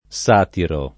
[ S# tiro ]